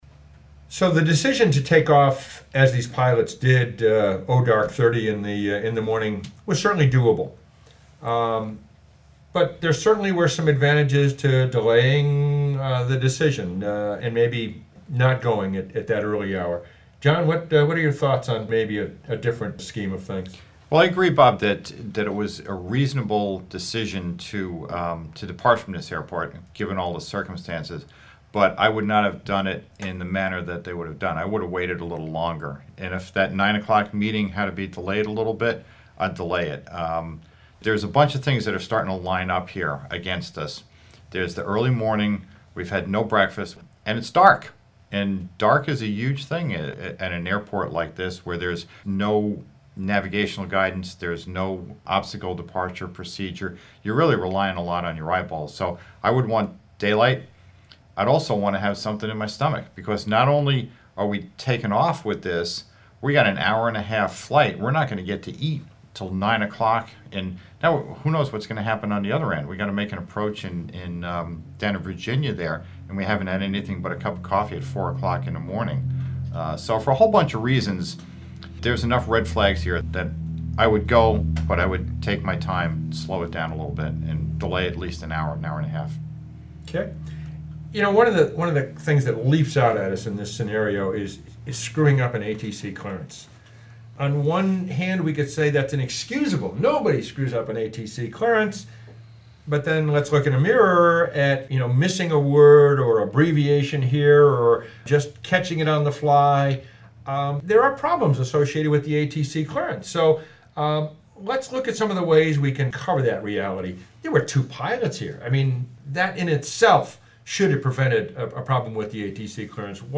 The scenario is based on a real, fatal accident and could have easily been prevented. Don’t miss the roundtable discussion for added insights.